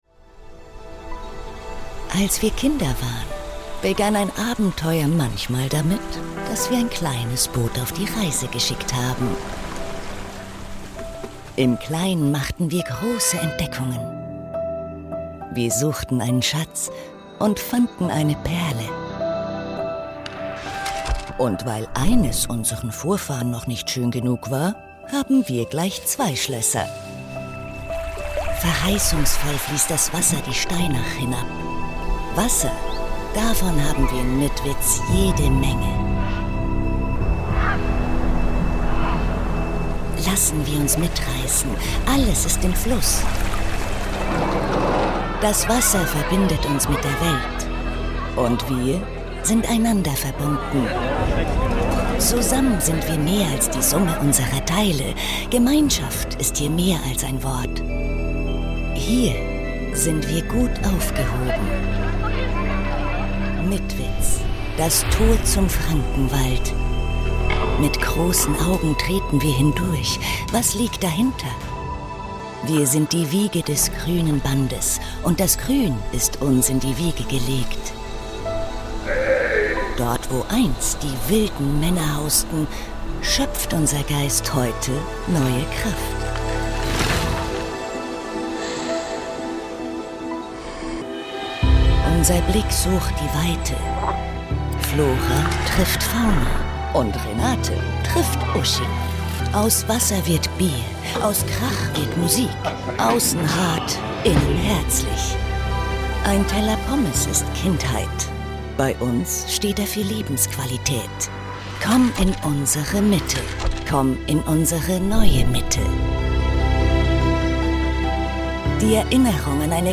sehr variabel
Mittel minus (25-45)
Commercial (Werbung)